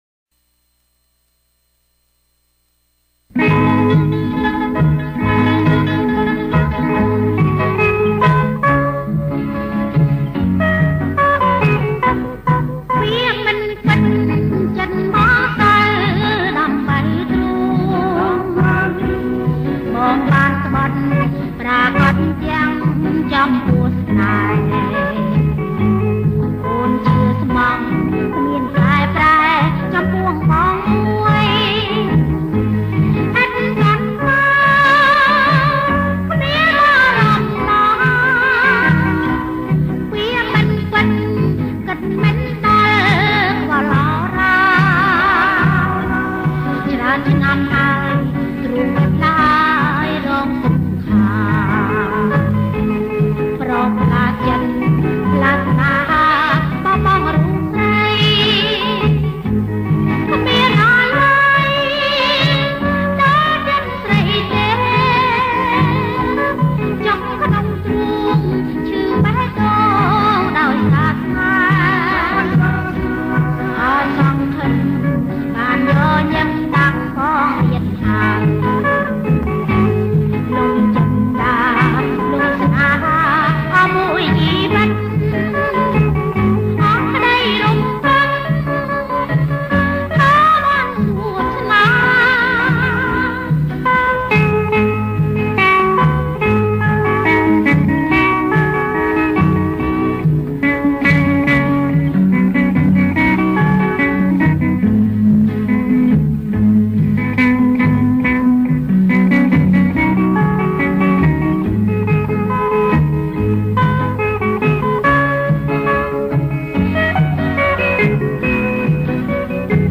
• បទភ្លេង បរទេស
• ប្រគំជាចង្វាក់ រាំវង់